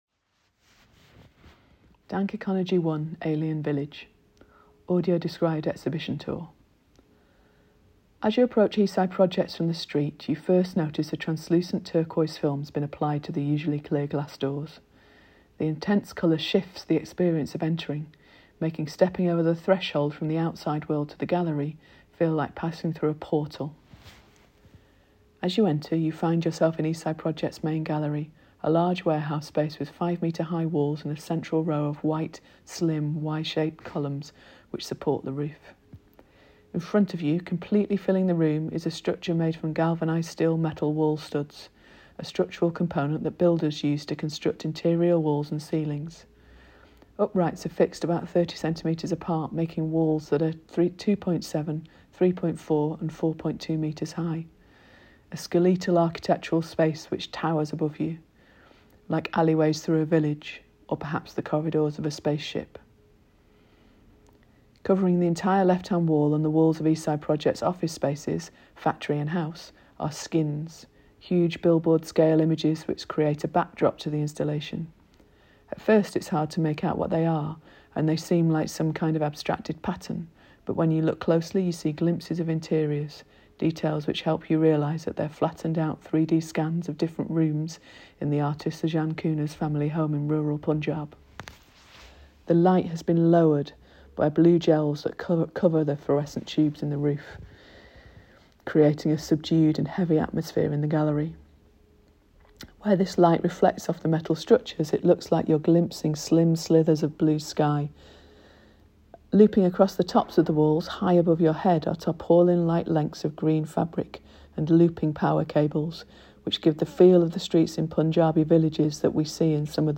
Audio Described Tour – dankEconogy1_ALIENVillage